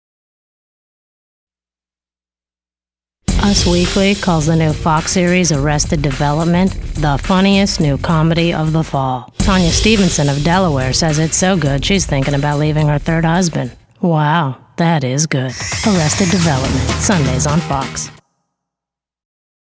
Female
Yng Adult (18-29), Adult (30-50)
Television Spots
Tv Promo Spot